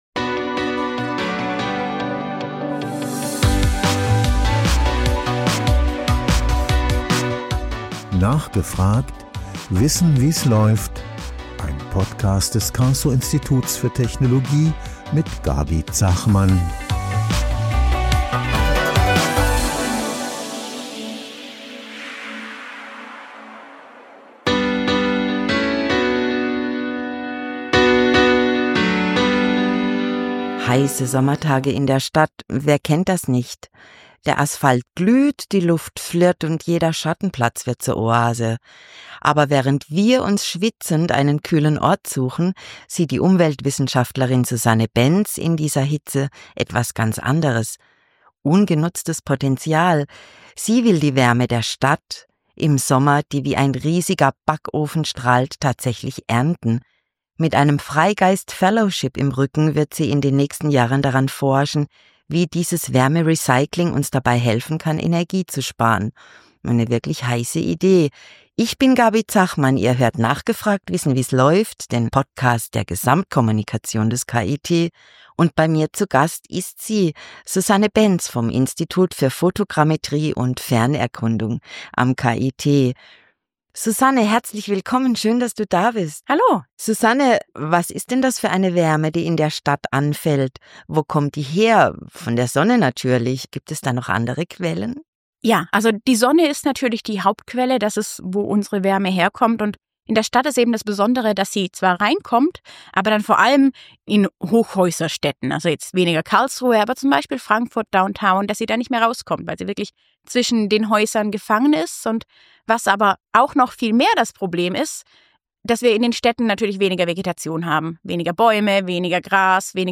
Mit einem Freigeist-Fellowship im Rücken wird sie in den nächsten Jahren daran forschen, wie dieses Wärmerecycling dabei helfen kann, Energie zu sparen. Im Interview-Podcast des Karlsruher Instituts für Technologie (KIT) sprechen unsere Moderatorinnen und Moderatoren mit jungen Forschenden, die für ihr Thema brennen.